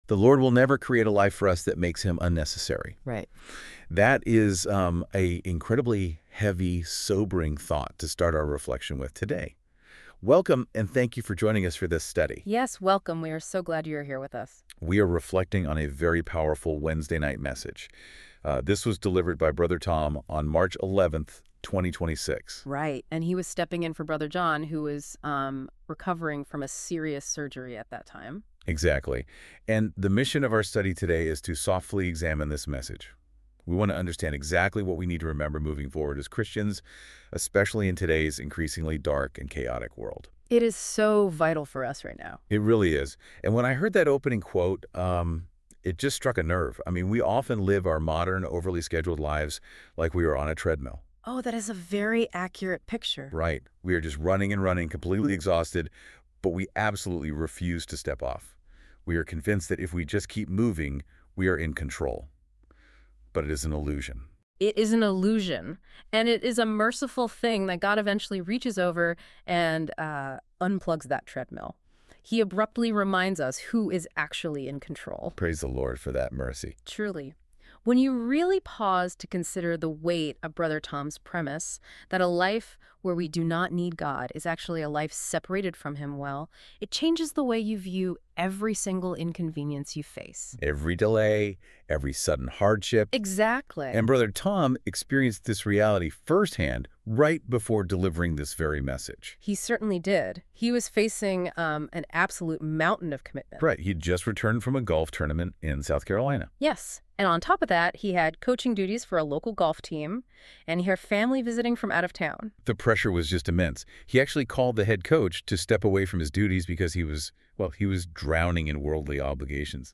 A Wednesday Lesson